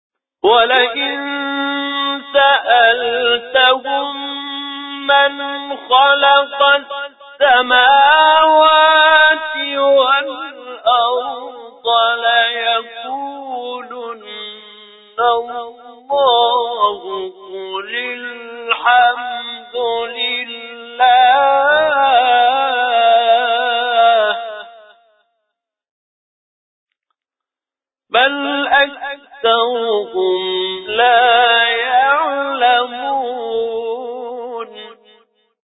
جواب مقام چهارگاه